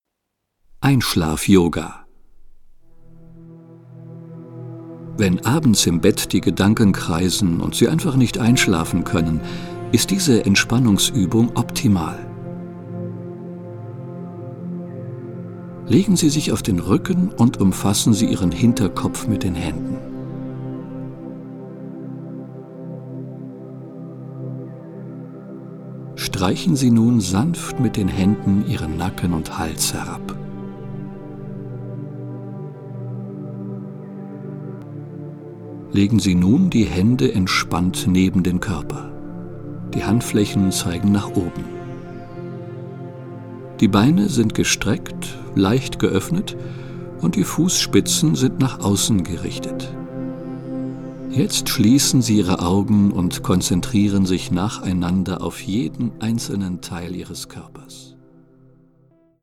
Die ruhige und einfühlsame Stimme des Sprechers
führt sie durch die einzelnen Übungen, die mit spezieller Entspannungsmusik unterlegt sind.